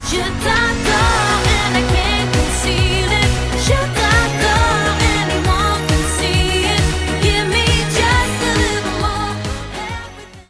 belgijska wokalistka